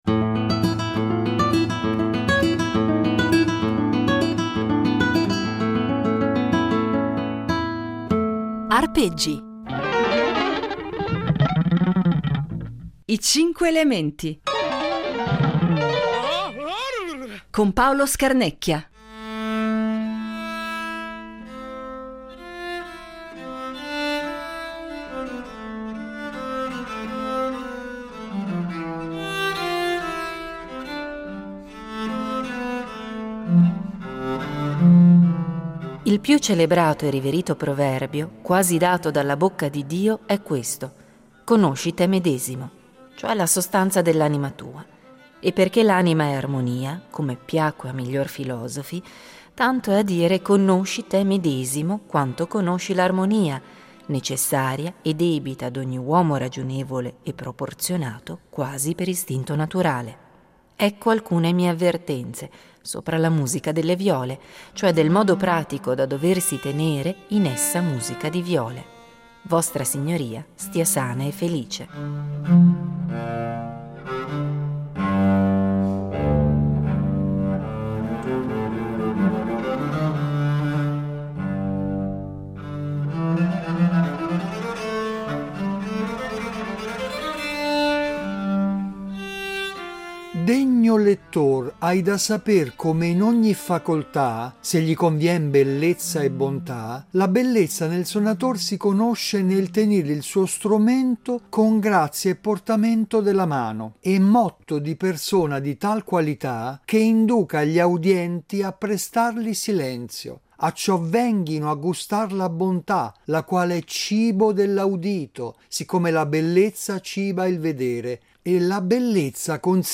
Viola da gamba (7./10)